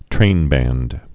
(trānbănd)